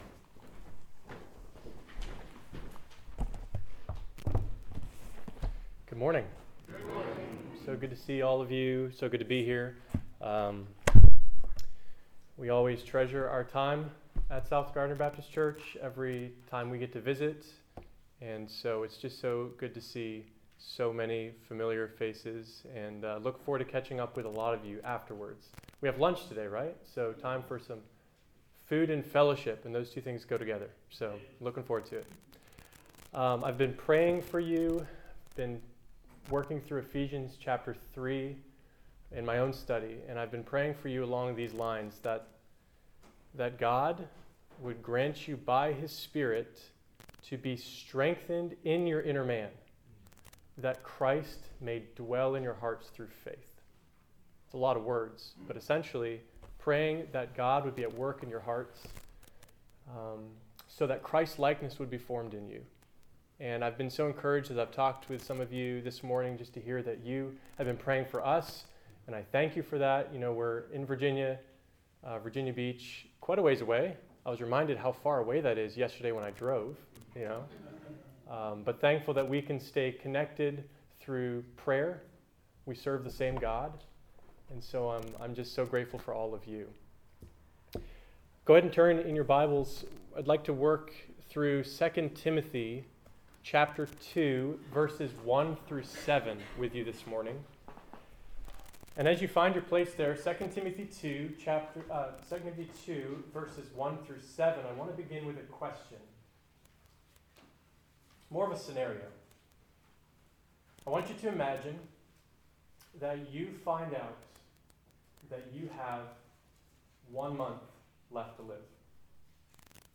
August 2025 2 Timothy Sunday Morning Scripture: 2 Timothy 2:1-7 Download: Audio